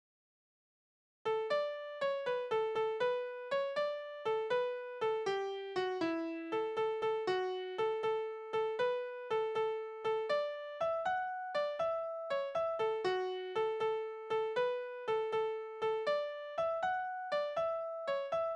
« O-4418-1 » Das Pulver, das ein Mönch erfand Soldatenlieder: Das Pulver, das ein Mönch erfand Aus Schwefel, Holz u. Kohlen, das ist in aller Welt bekannt, dem Teufel abgestohlen.